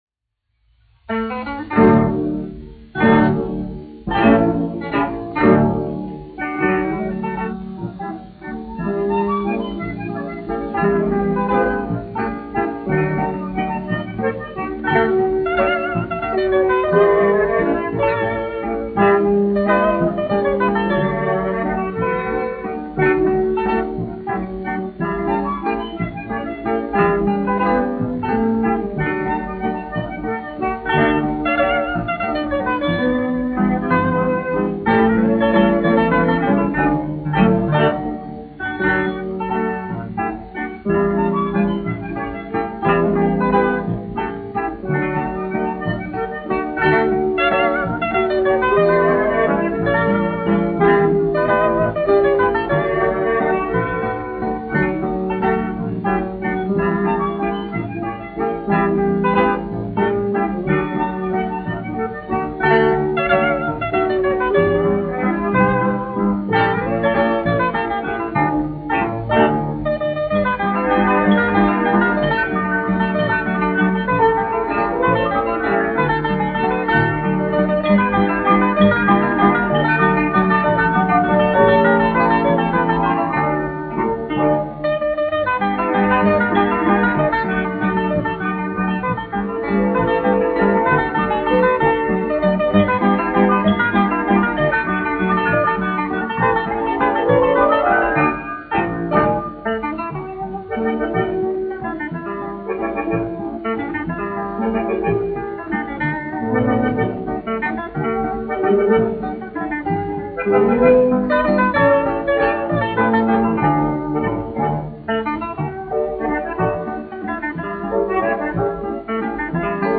1 skpl. : analogs, 78 apgr/min, mono ; 25 cm
Lendleri
Tautas dejas